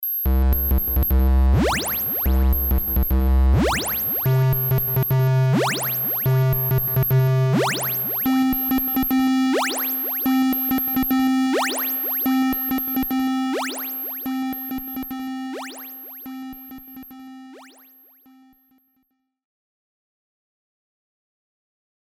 オシレータ2のみをオンにした状態で同じフレーズを演奏させた例です。
Spire_soundmake_demo_013_osc2.mp3